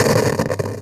radio_tv_electronic_static_05.wav